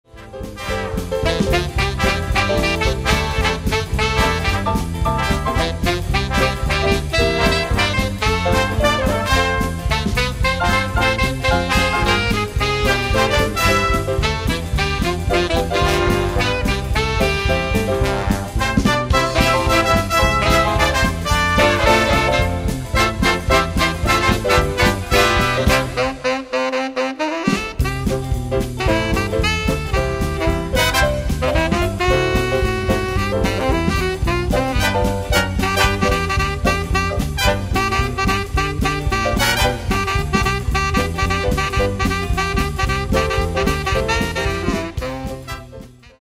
Solist am Tenorsaxophon